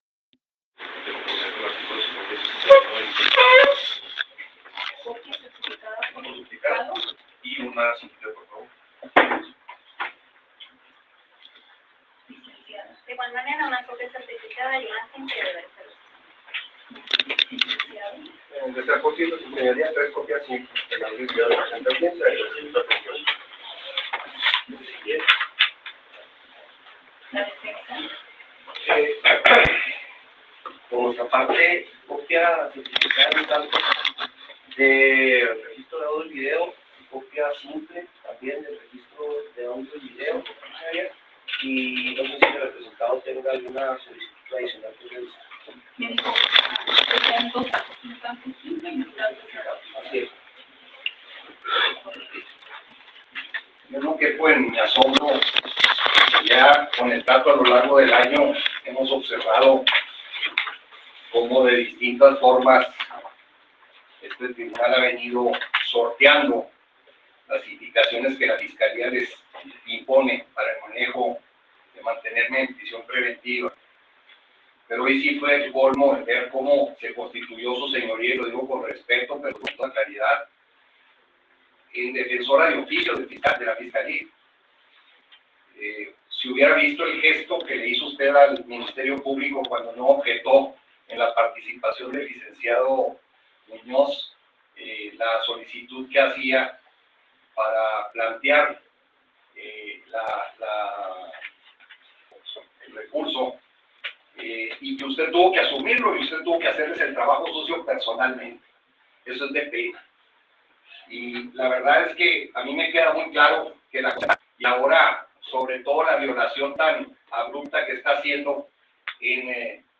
Enfurece César Duarte, grita a una jueza y desacata la instrucción de desalojar el tribunal